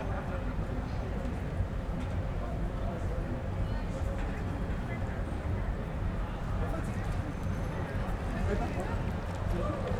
Environmental
UrbanSounds
Streetsounds
Noisepollution